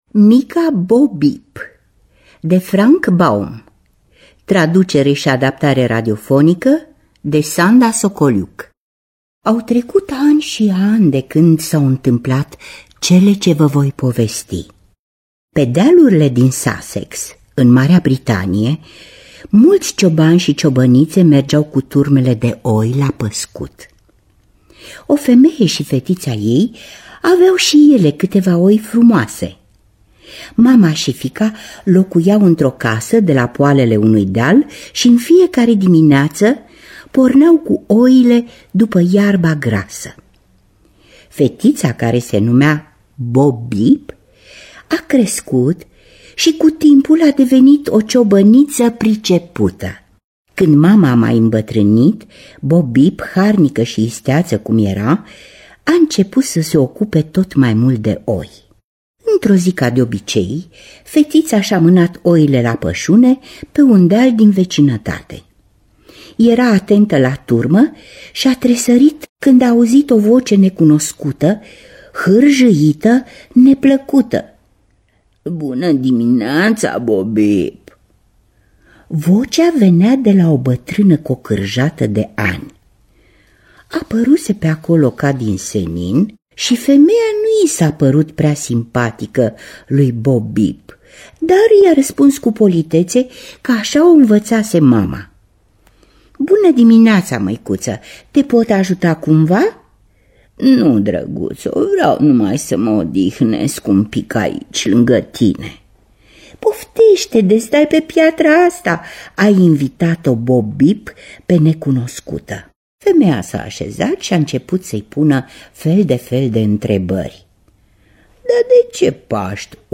Lectura: Mariana Mihuț.